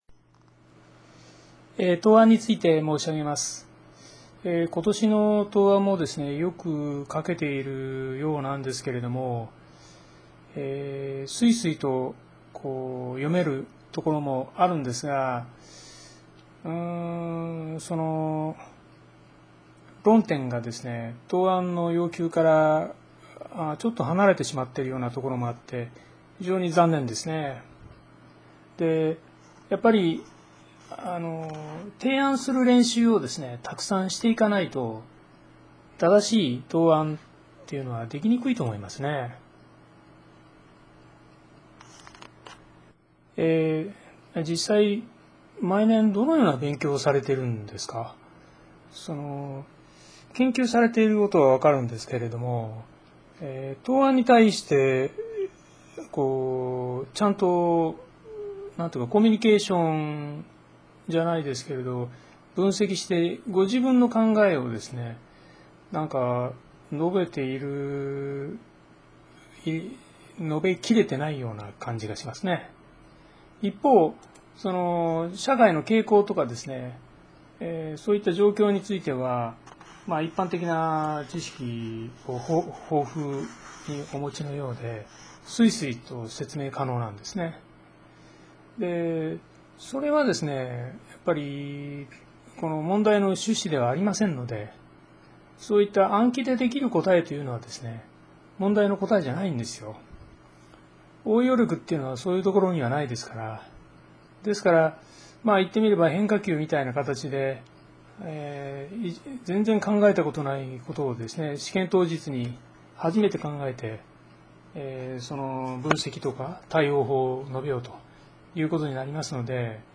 音声ガイドによるコーチング指導内容(13分54秒、5分33秒)がダウンロードされますのでお聞きください＞